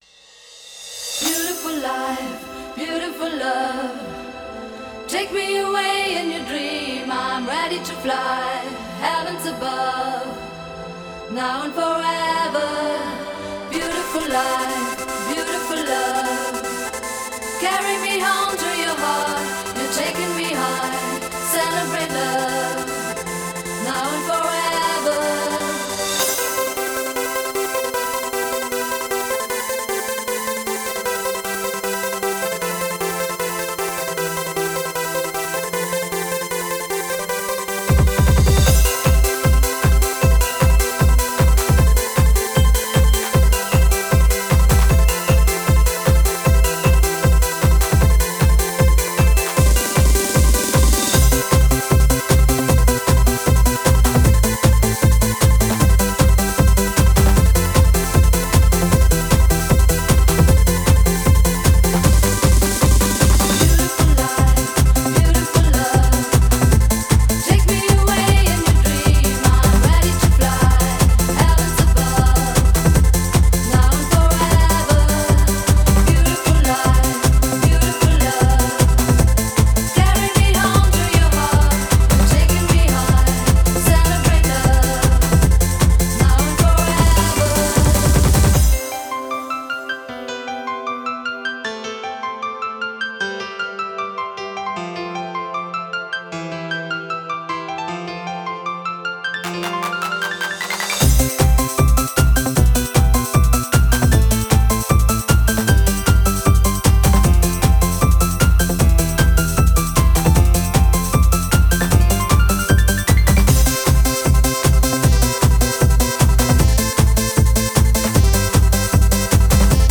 Genre: Happy Hardcore.